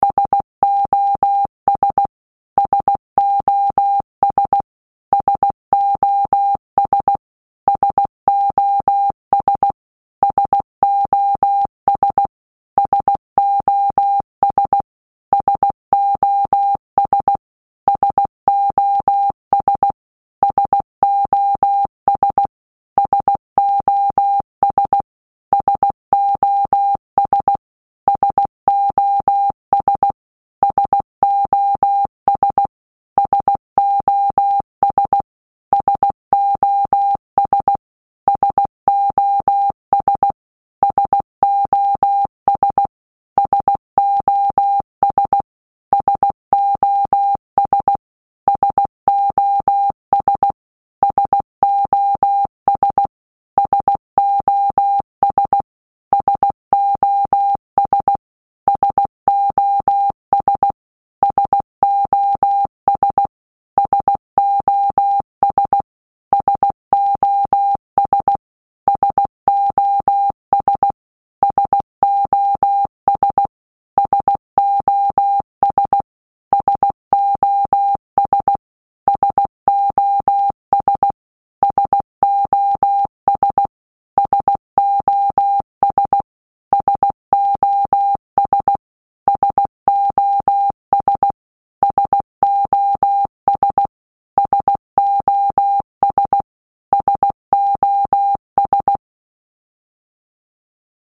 SOS sound in Morse Code (Slow)